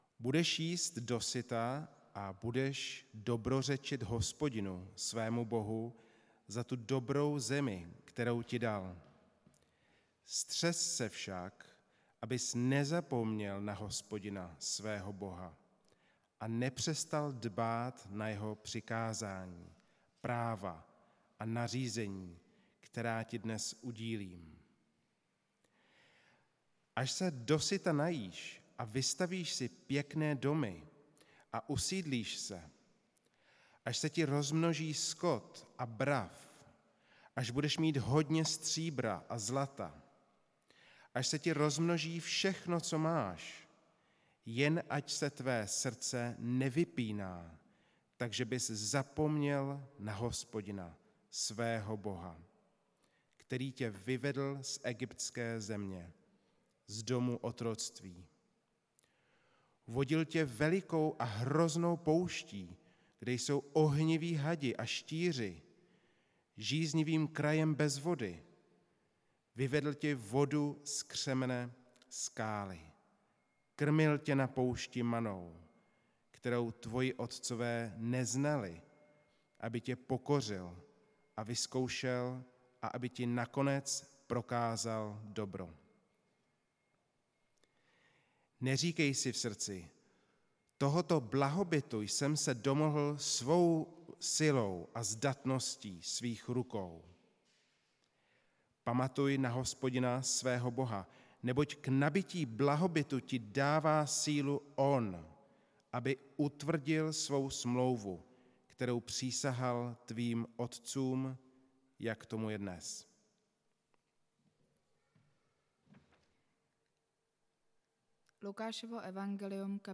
Nedělní kázání – 10.7.2022 Podobenství o boháčovi a stodolách